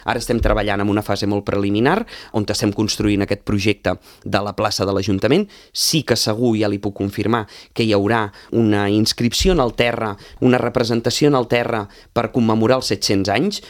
El tinent d’Alcaldia d’Activitat Cultural, Josep Grima, ha explicat a l’entrevista política de RCT que el repte és seguir millorant, però que no es planteja modificar el format de tres dies malgrat les cues per entregar les cartes als Patges Reials o participar en alguns tallers.